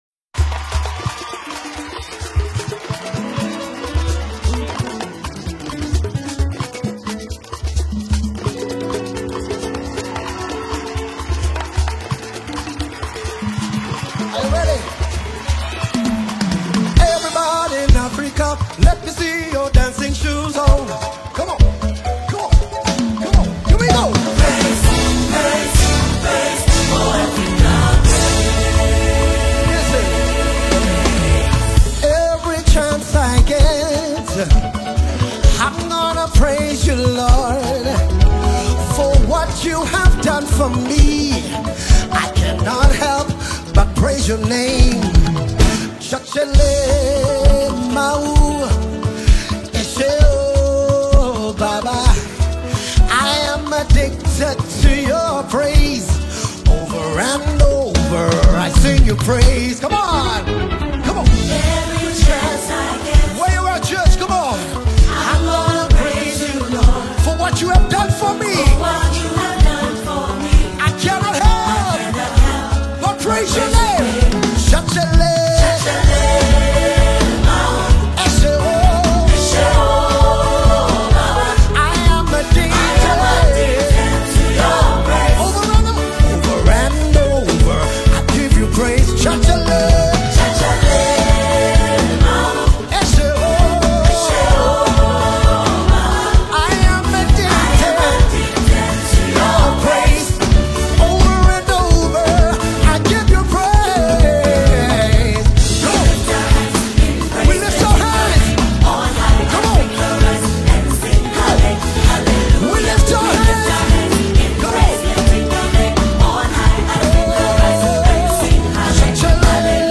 South African Gospel
Genre: Gospel/Christian